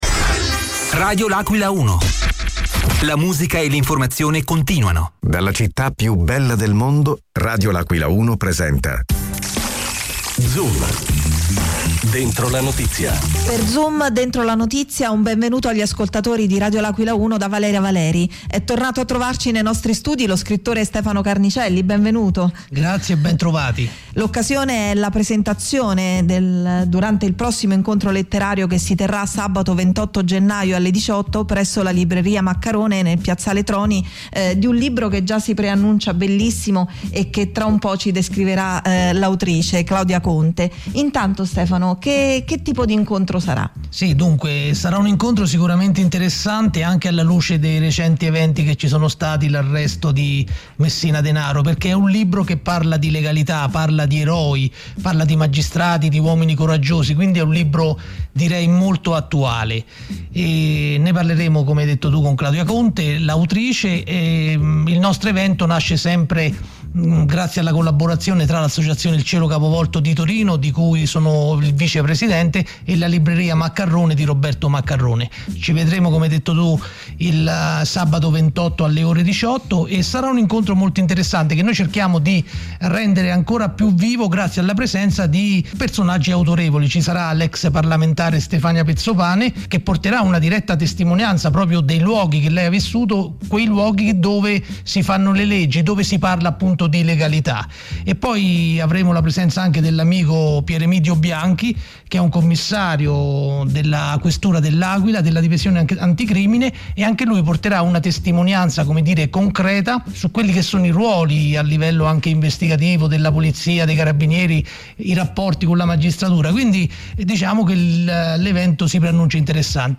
L’AQUILA – Sono stati ospiti negli studi di Radio L’Aquila 1